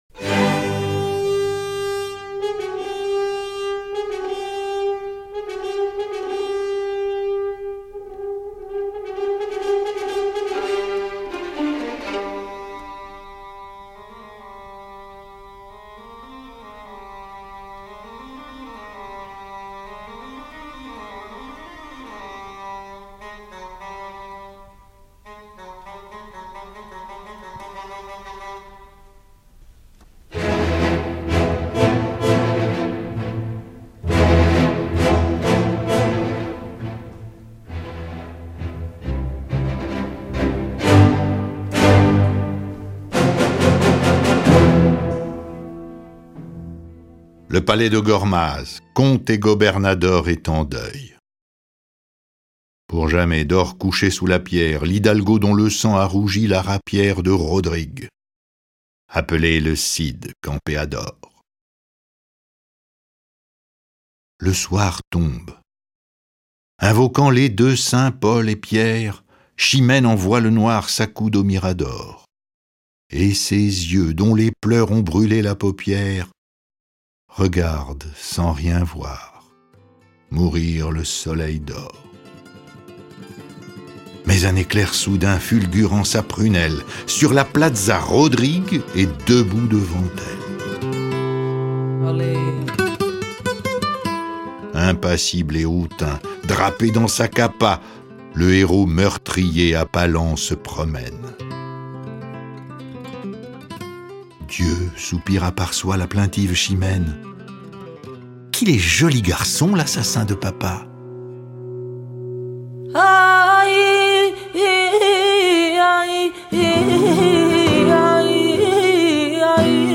Mise en voix très personnelle – 05/2008